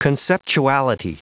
Prononciation du mot conceptuality en anglais (fichier audio)
Prononciation du mot : conceptuality